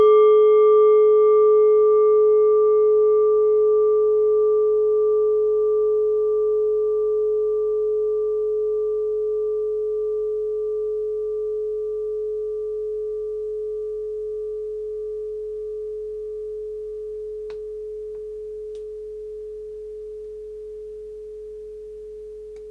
Klangschale Nepal Nr.37
Klangschale-Gewicht: 1010g
Klangschale-Durchmesser: 14,7cm
(Ermittelt mit dem Filzklöppel)
klangschale-nepal-37.wav